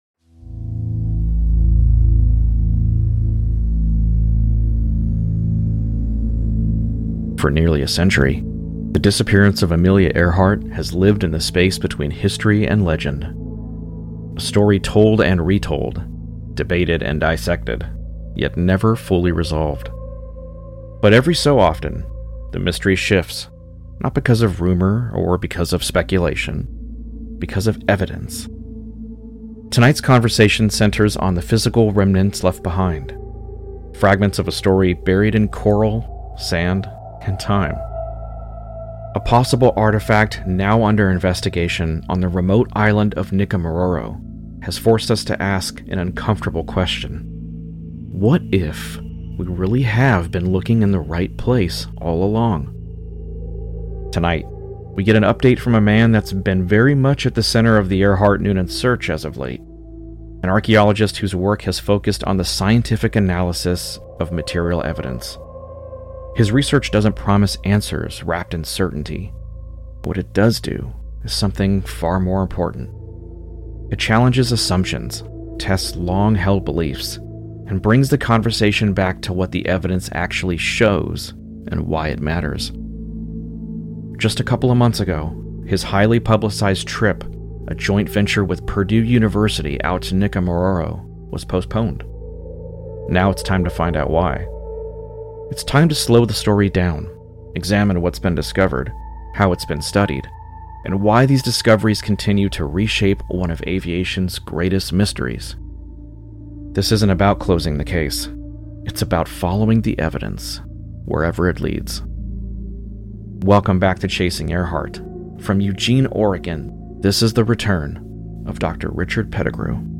Tonight’s conversation centers on the physical remnants left behind — fragments of a story buried in coral, sand, and time.